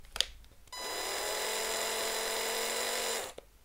Звуки шуруповерта
Медленный режим работы шуруповерта